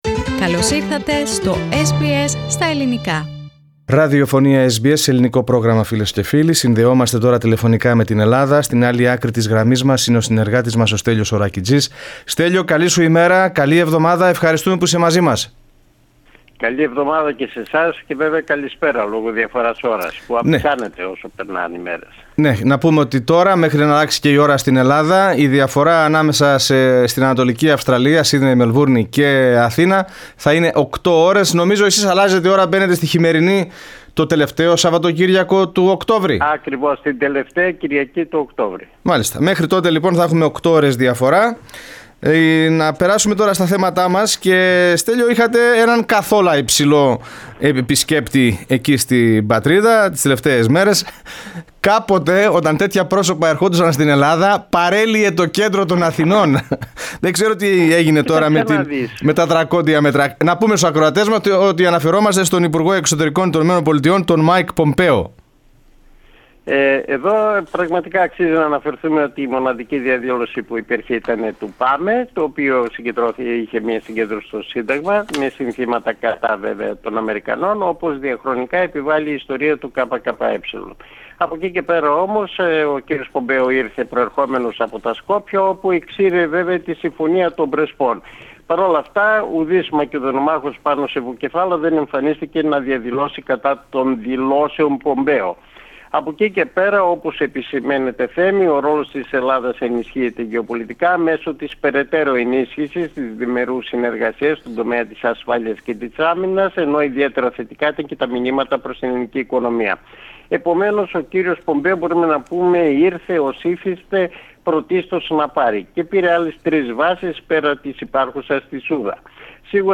ανταπόκριση